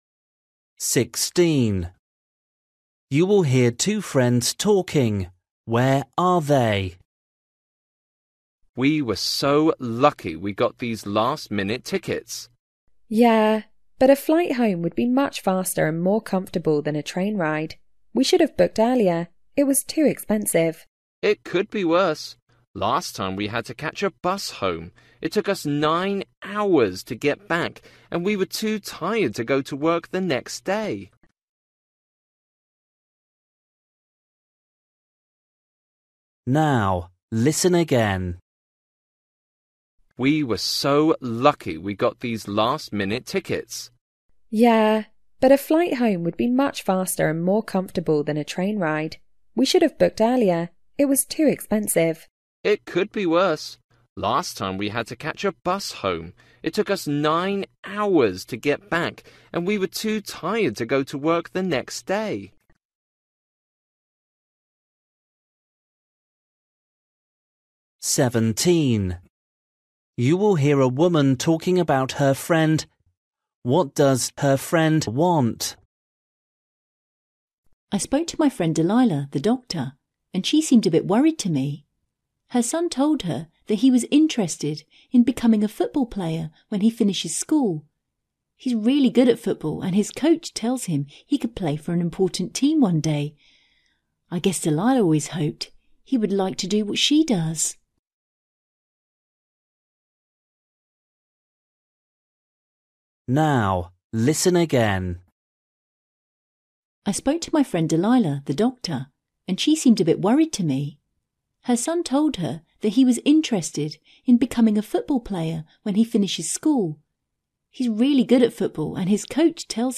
Listening: everyday short conversations
16   You will hear two friends talking. Where are they?
18   You will hear a police officer talking to a man. What’s the problem?
20   You will hear two colleagues talking at the office. Why is the man tired?